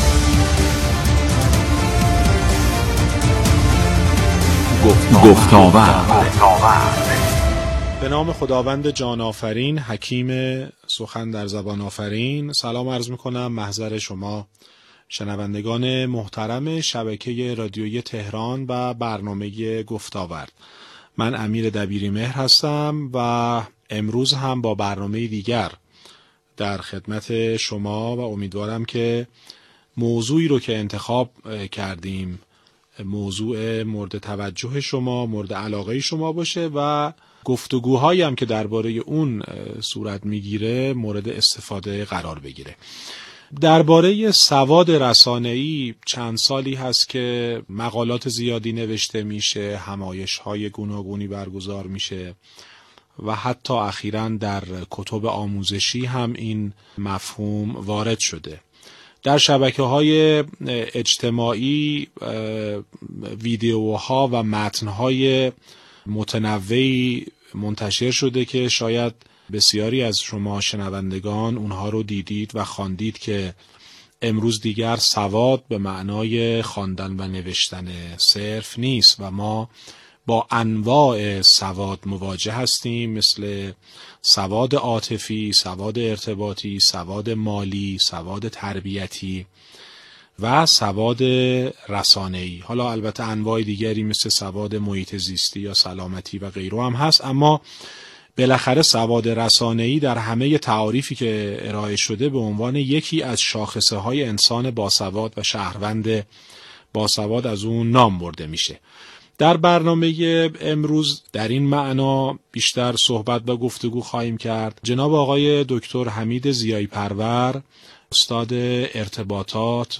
مشروح گفتگو درباره سواد رسانه ای در برنامه گفتاورد رادیو تهران را در لینکهای زیر بشنوید